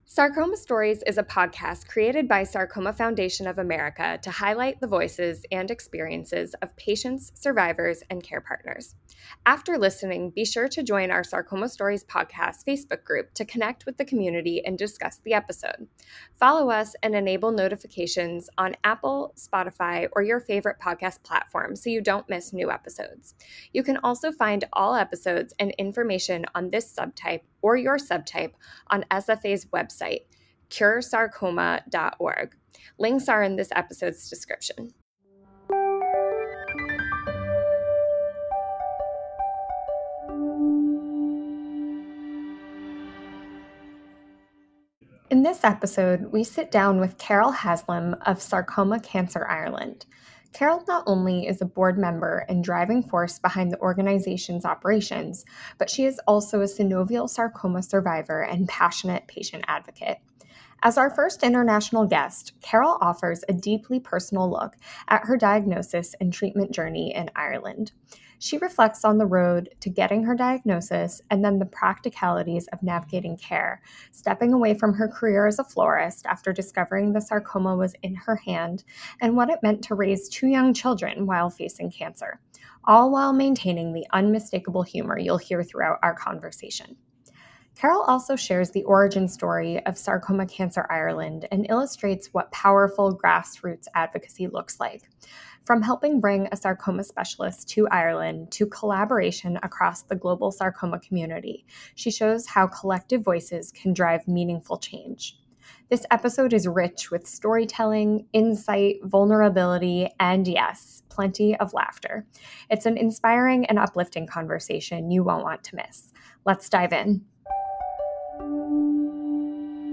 Sarcoma Stories is the only podcast highlighting the journey and experiences of people living and surviving sarcoma. Each episode will feature a guest sharing their sarcoma journey, resources they found valuable and more.